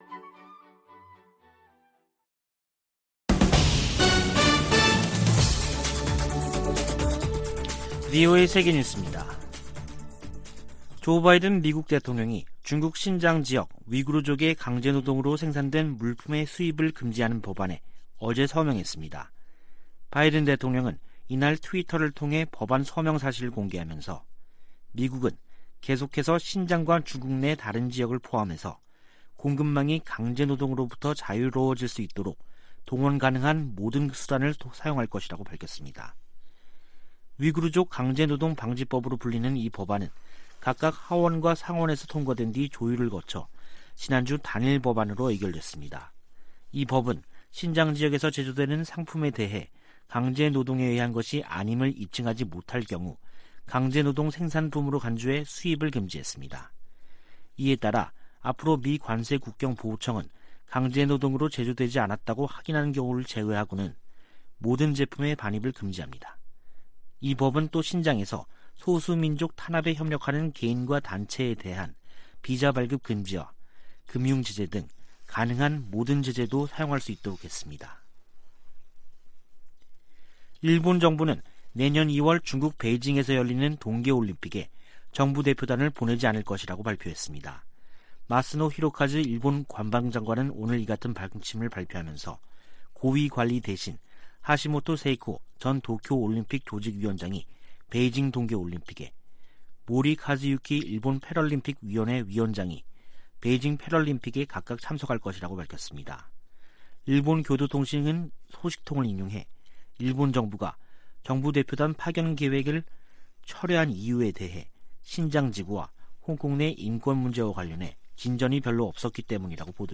VOA 한국어 간판 뉴스 프로그램 '뉴스 투데이', 2021년 12월 24일 2부 방송입니다. 미국 내 구호단체들은 올 한 해가 대북 지원 사업에 가장 도전적인 해였다고 밝혔습니다. 미 델라웨어 소재 'TD 뱅크그룹'이 대북 제재 위반 혐의로 11만5천 달러 벌금에 합의했다고 미 재무부가 밝혔습니다. 한국과 중국이 4년 반 만에 가진 외교차관 전략대화에서 종전선언 등에 협력 방안을 논의했습니다.